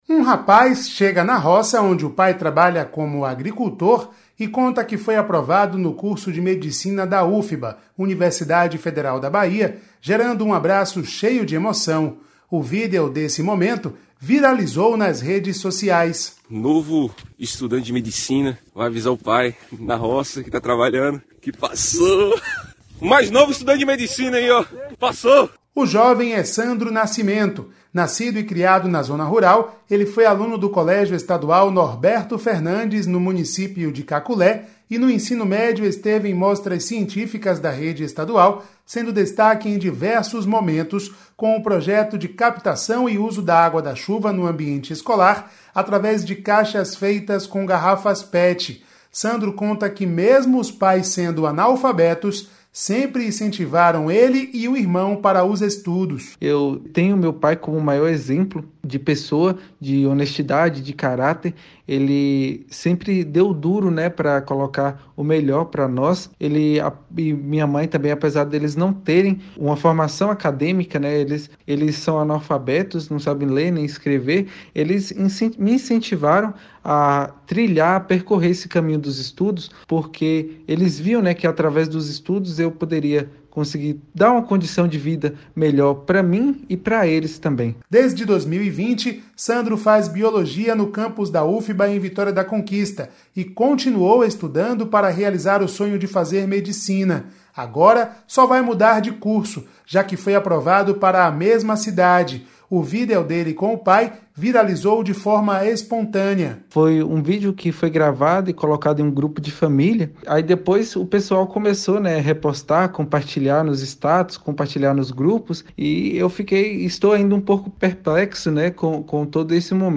SOBE SOM